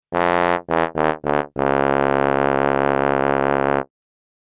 Comical Sad Trombone Fail Sound Effect
Description: Comical sad trombone fail sound effect. This iconic trombone sound marks a fail or a botched attempt.
Comical-sad-trombone-fail-sound-effect.mp3